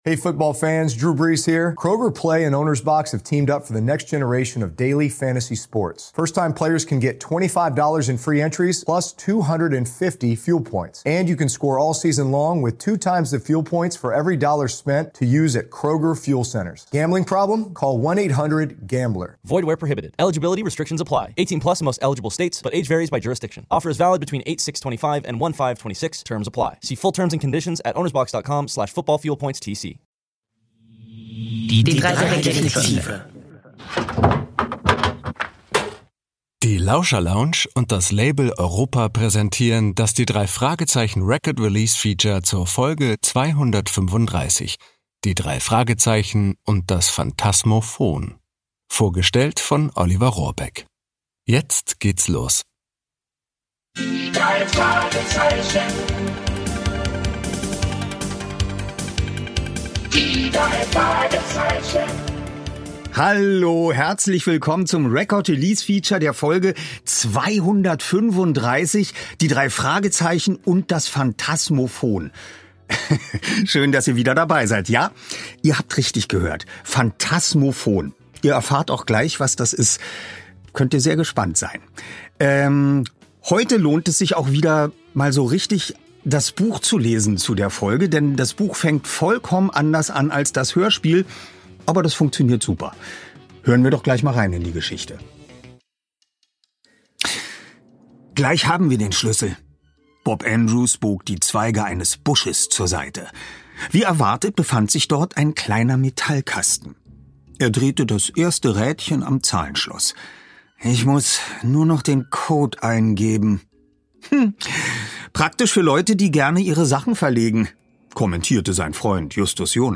Olivers Vorstellung der neuen Folge beginnt mit einem Auszug aus dem Buch, das dieses Mal komplett anders startet als das Hörspiel. Und wenn ihr schon immer mal wissen wolltet, ob Oliver Rohrbeck einen Lieblings-Blacky-Spruch hat, dann hört unbedingt rein!
Sprecher: Oliver Rohrbeck